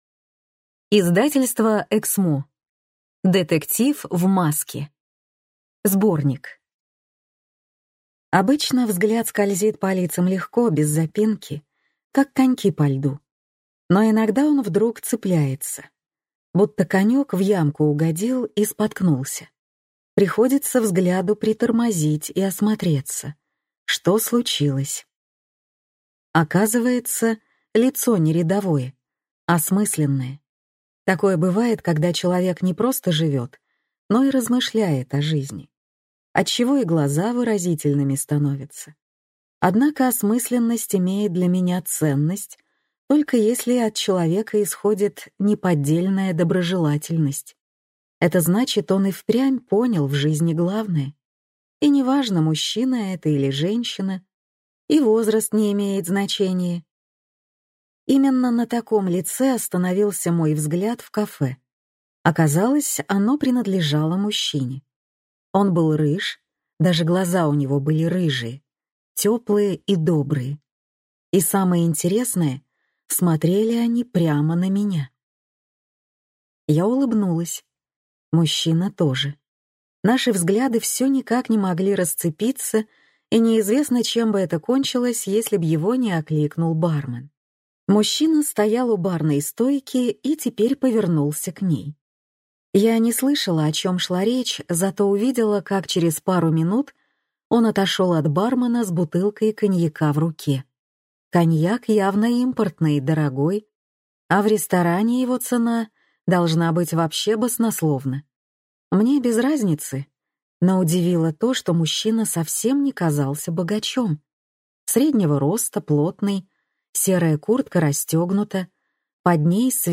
Аудиокнига Детектив в маске | Библиотека аудиокниг